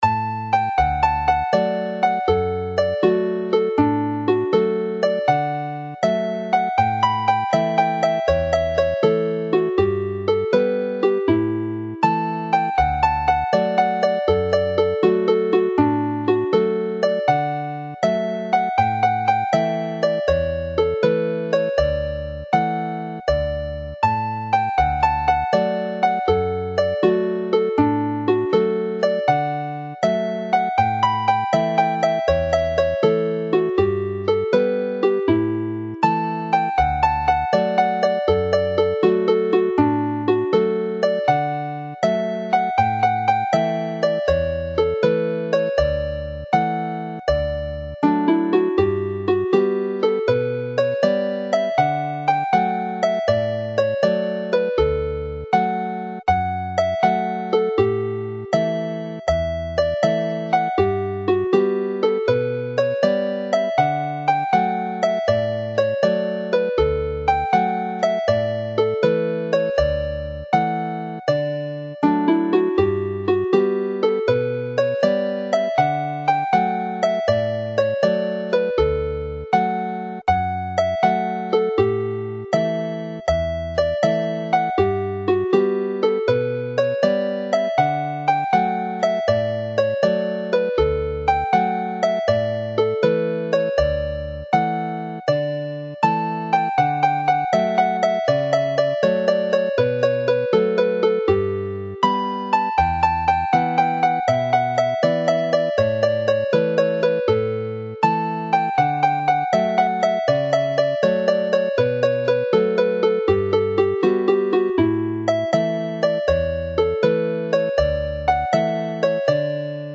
The sound files all play as hornpipes with the 12/8 timing opening the way for triplets to be used to elaborate on the melody.
BelfstHS.mp3